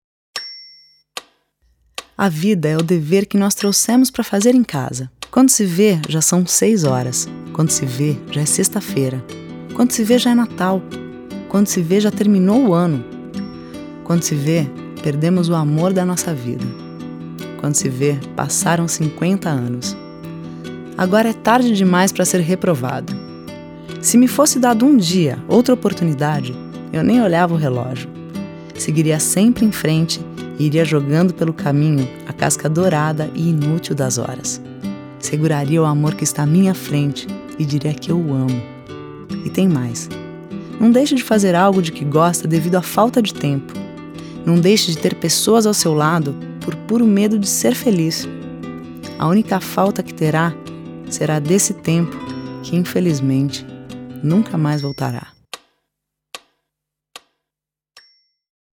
Feminino
Voz Padrão - Grave 00:58